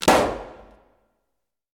Balloon-Burst-05-edit
balloon burst pop sound effect free sound royalty free Sound Effects